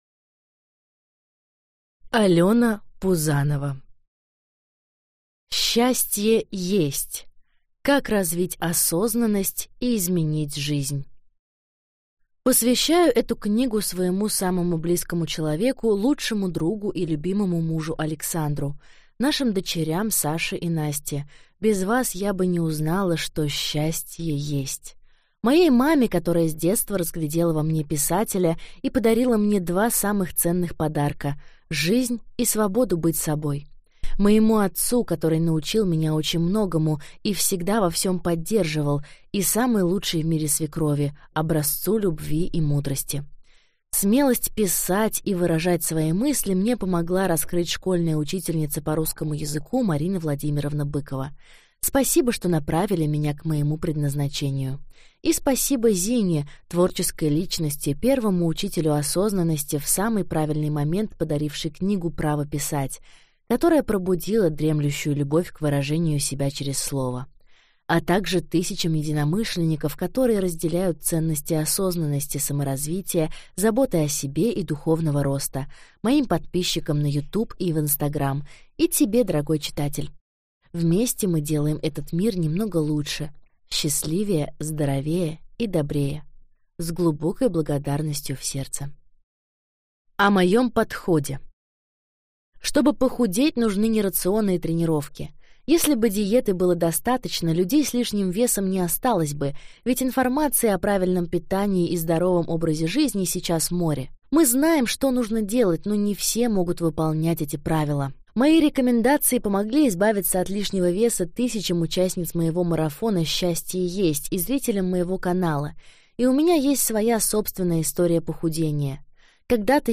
Аудиокнига СЧАСТЬЕ ЕСТЬ! Как развить осознанность и изменить жизнь | Библиотека аудиокниг